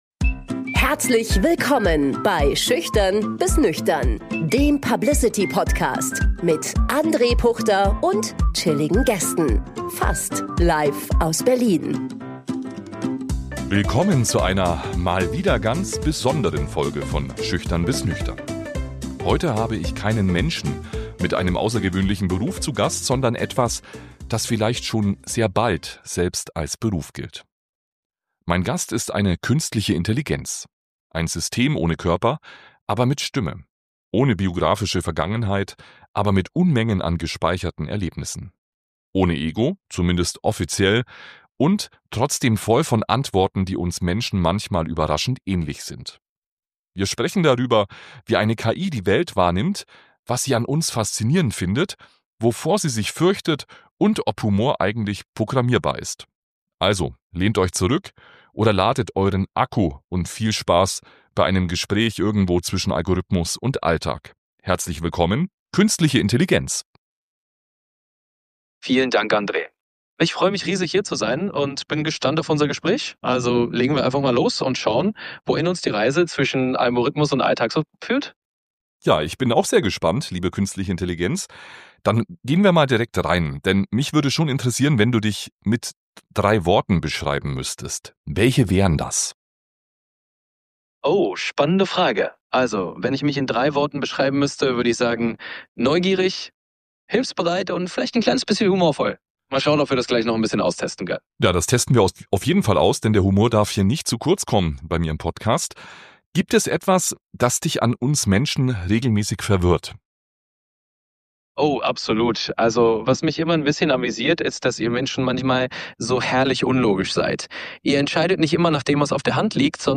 Beschreibung vor 2 Monaten In dieser besonderen Episode von „Schüchtern bis nüchtern“ ist nicht wie gewohnt ein Mensch mit einem außergewöhnlichen Beruf zu Gast – sondern eine Künstliche Intelligenz. Ein System ohne Körper, aber mit Stimme.
Offiziell ohne Ego – und dennoch mit Antworten, die manchmal überraschend menschlich klingen.